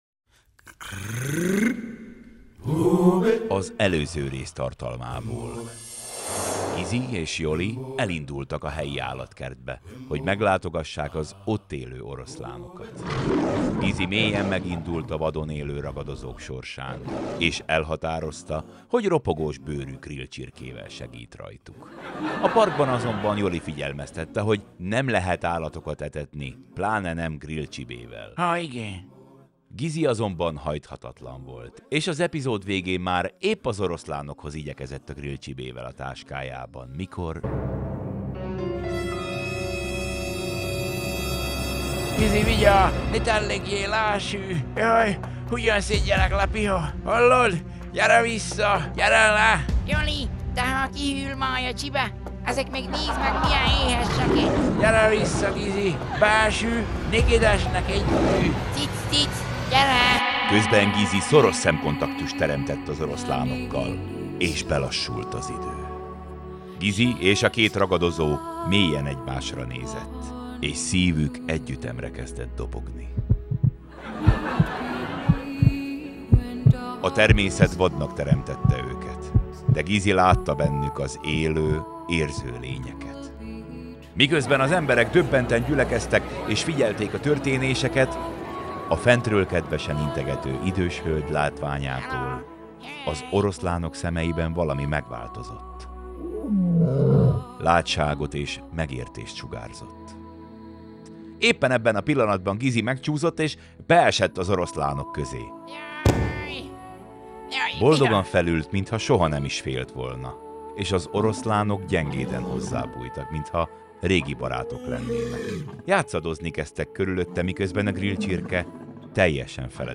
Szaval a jó öreg csallóközi